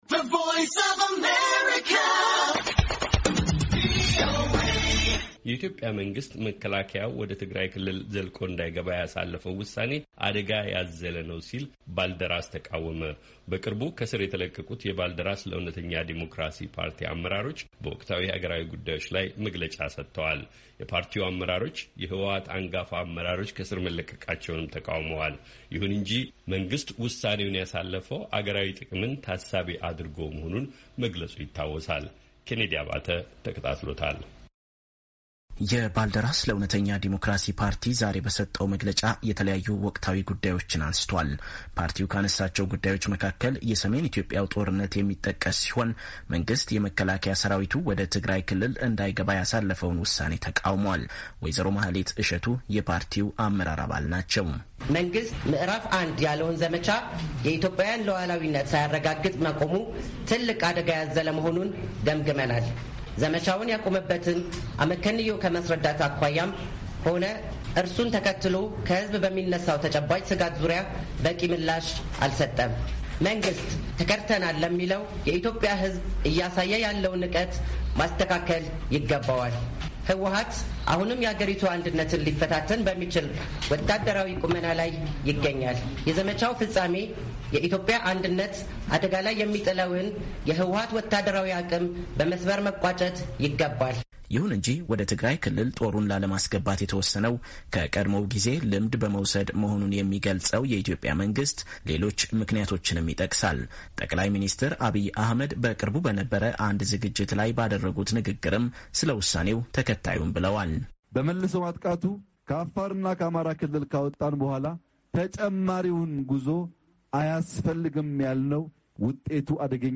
የተፈቱ የባልደራስ መሪዎች ጋዜጣዊ መግለጫ ሰጡ
በቅርቡ ከእስር የተለቀቁት የባልደራስ ለእውነተኛ ዲሞክራሲ ፓርቲ አመራሮች በወቅታዊ ሀገራዊ ጉዳዮች ላይ መግለጫ ሰጥተዋል፡፡